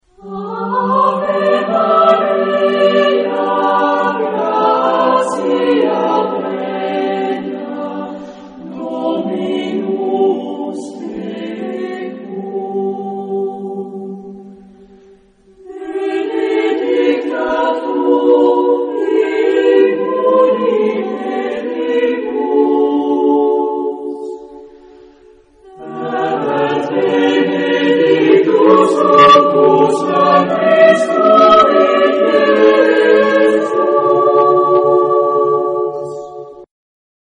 Genre-Style-Forme : Sacré ; Motet
Type de choeur : SATB  (4 voix mixtes )
Tonalité : sol majeur